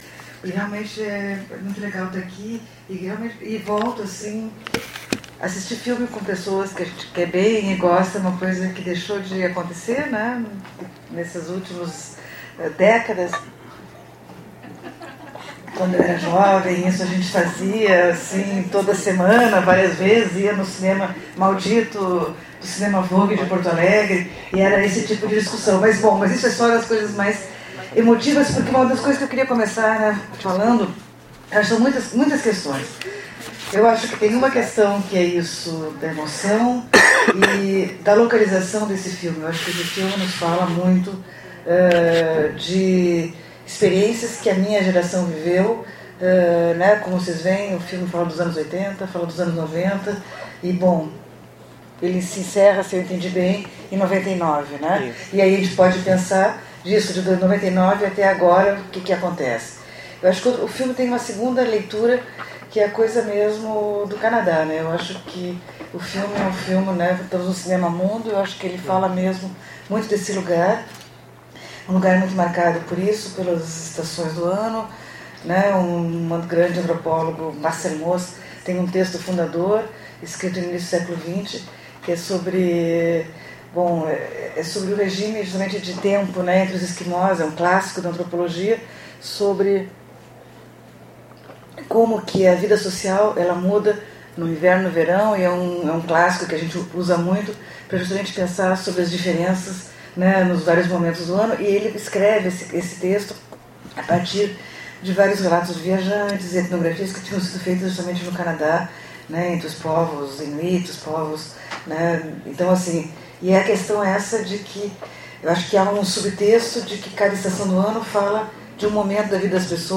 Comentários dos debatedores convidados